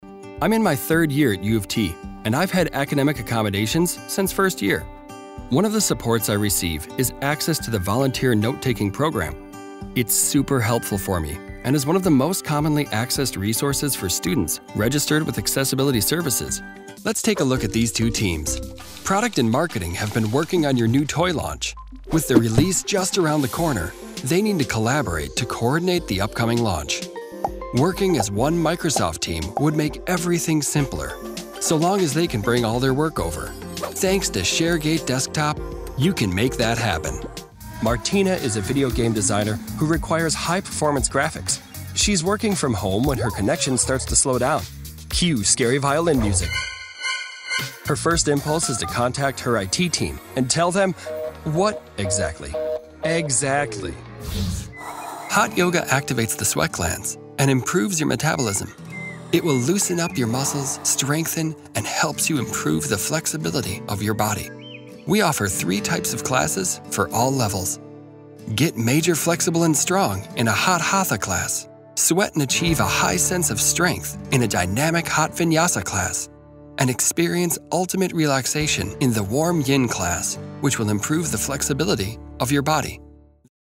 Natural, Llamativo, Cool, Amable, Cálida
Explicador
He is often described as warm and charismatic, with a clear, articulate delivery that conveys both sincerity and a playful sense of humor.